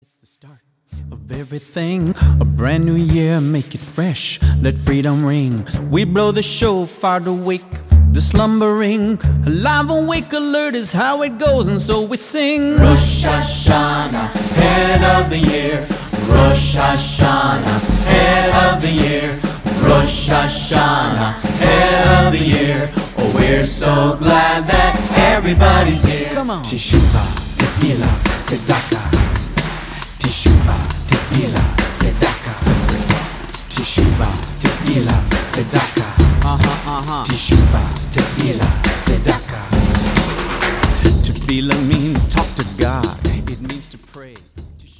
Funky Jewish music for the whole family!